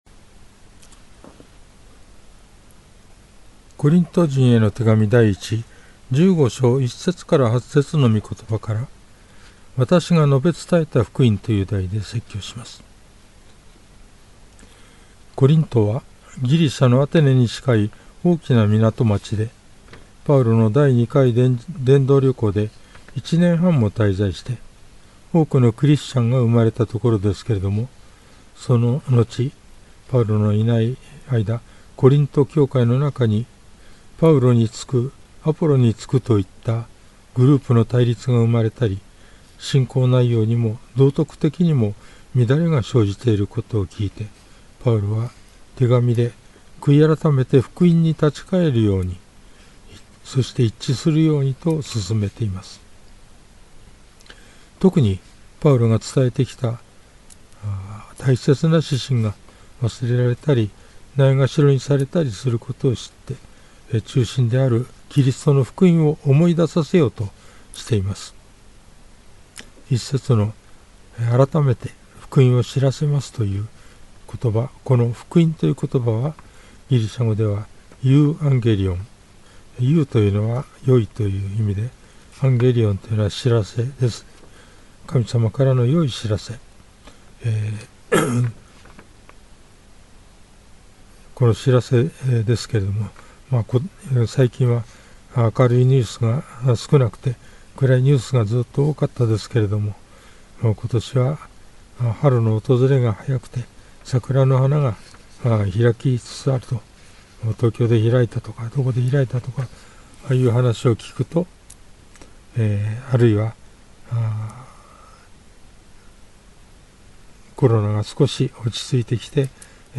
主日礼拝
説教
♪ 事前録音分